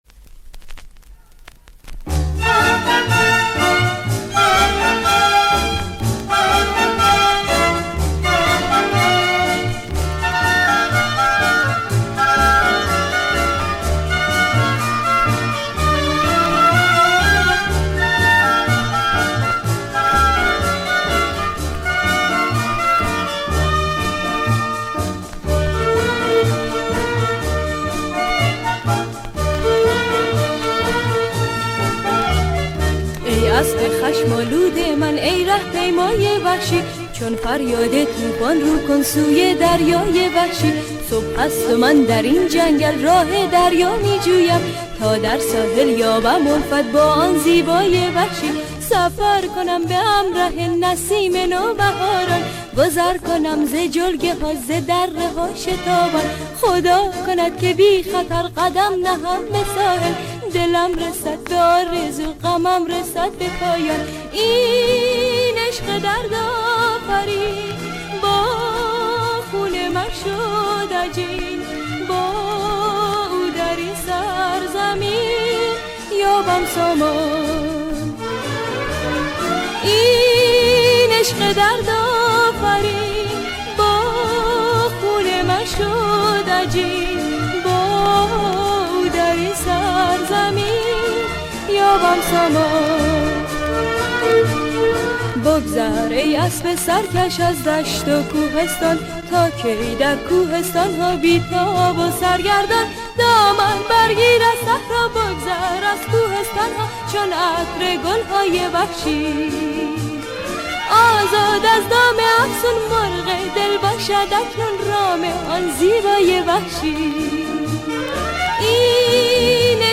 نسخه‌ی با کیفیت